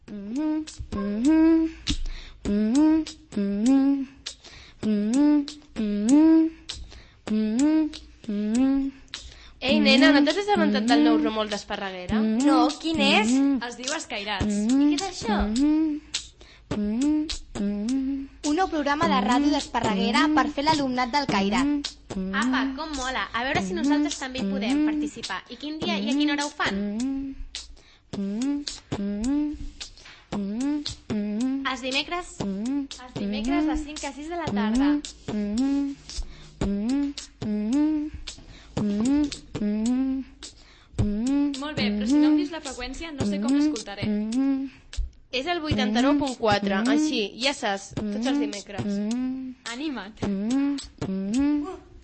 Promoció del programa
Infantil-juvenil